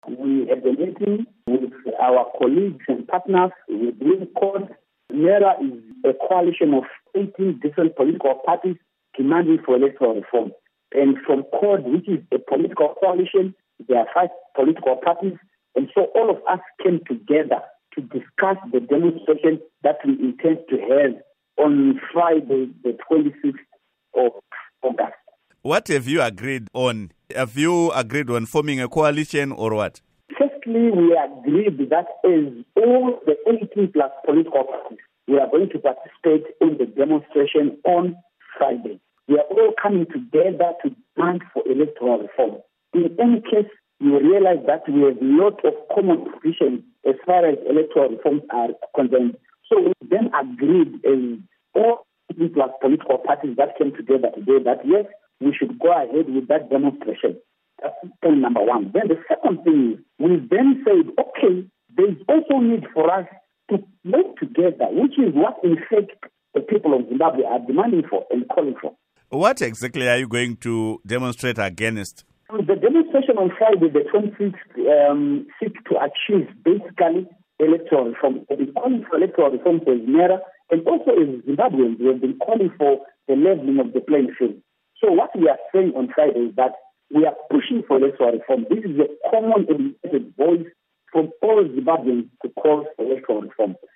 Interview With Jacob Ngarivhume